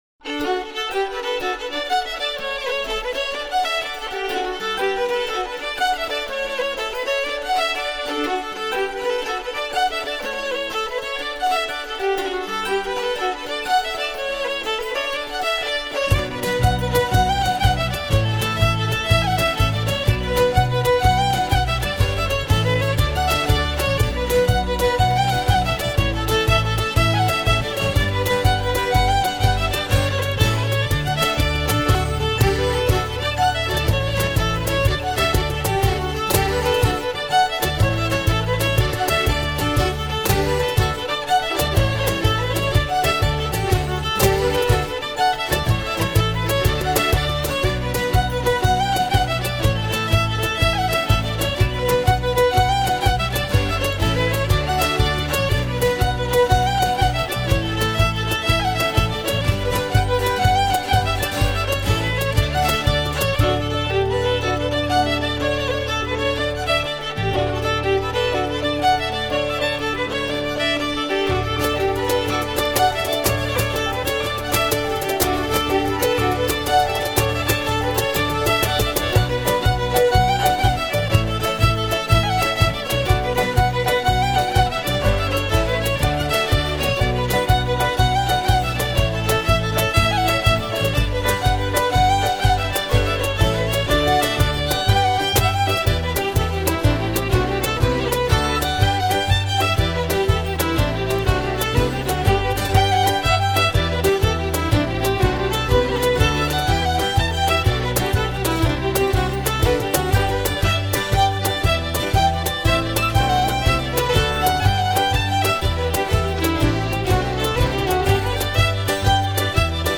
Midlands-based Celtic Ceilidh band for hire.
Fiddle, Guitar/Vocals/Bodhran, Keys/Whistles, Cajon Drum